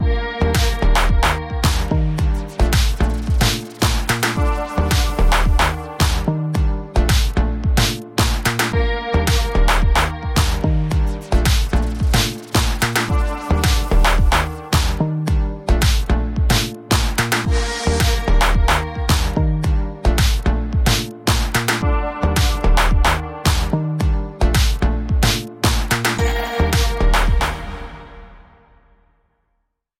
Example 2: Creating an Afrobeats Track